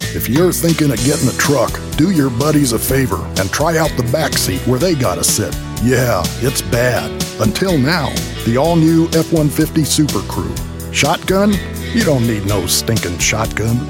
Male
a deep baritone voice with some grit and a tone of wisdom, authority, warmth and trust
Truck Commercial Tv/Radio